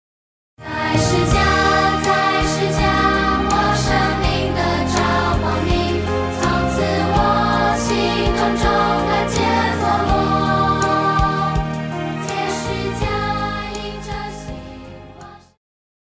流行-合唱,童声
乐团
圣歌,流行音乐,教会音乐
歌唱曲
声乐与伴奏
有主奏
有节拍器
为了浅显易懂，除了把现代乐风融入传统圣诗旋律，针对部份艰涩难懂的歌词，也稍作修饰，尽可能现代化、口语化；